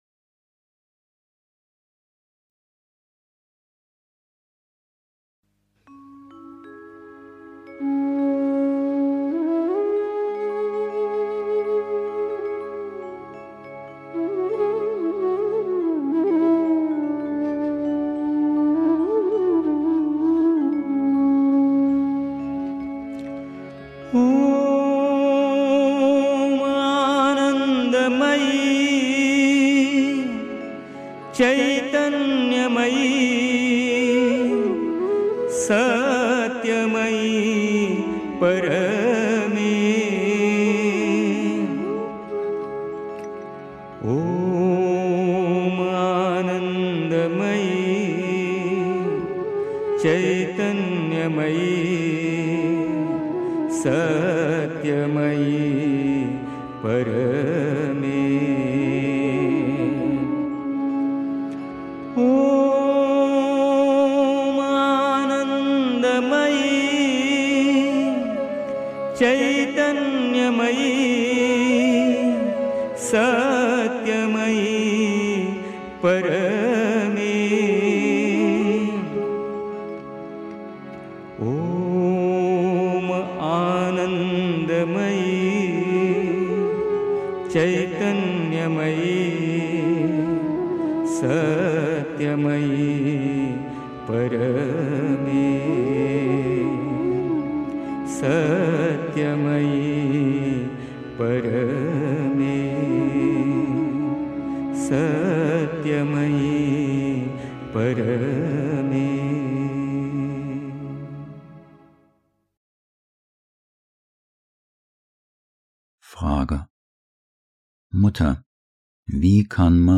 1. Einstimmung mit Musik. 2. Den Willen stärken (Die Mutter, The Sunlit Path) 3. Zwölf Minuten Stille.